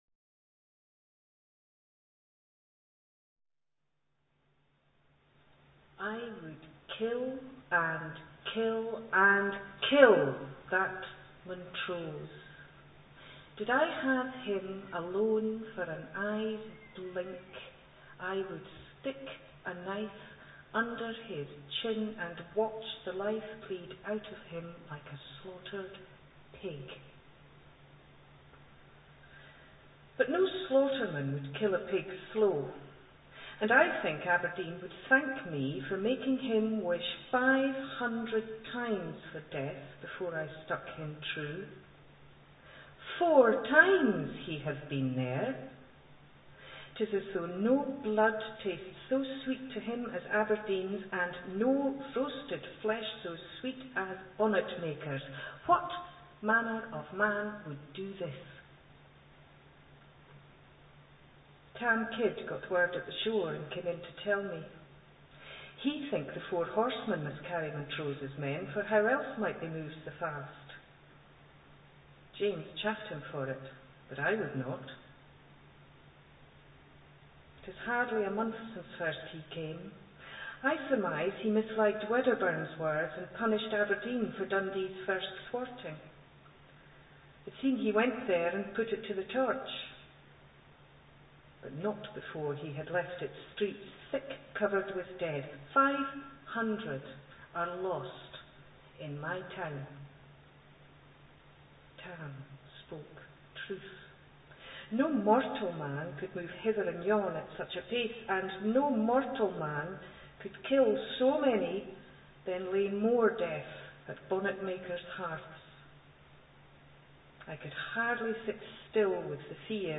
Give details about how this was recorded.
First reading extract, at the Tolbooth Theatre Stirling The auditorium where the reading was held THESE READINGS ARE EXTRACTS FROM A RECORDING MADE BY THE TOLBOOTH THEATRE IN STIRLING